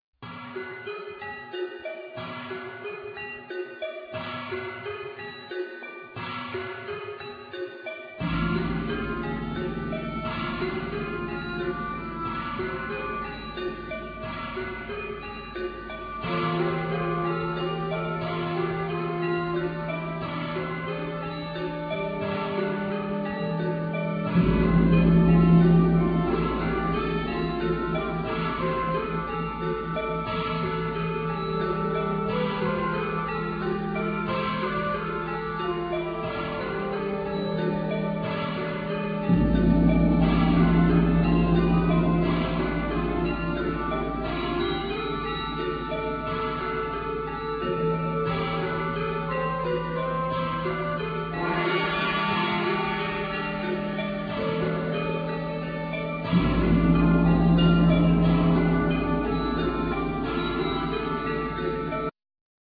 Vocal,Portative organ
Percussions
Bombarde,Shofar,Recorder,Santur,Vocal
Lute
Ud
Didjeridu,Shakuhachi,Jew's harp
Alto fiddle